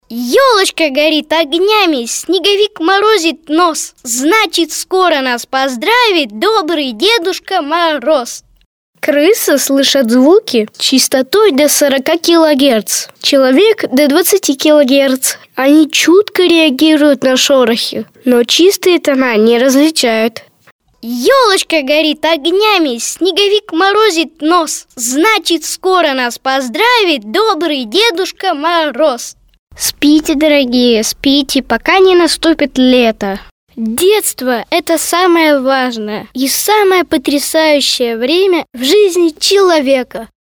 Детский голос. Демо
Отличный профессиональный звук: микрофон - Neumann TLM 103, микрофонный процессор - PreSonus ADL 700, звуковая карта - Steinberg UR12, тон-кабина.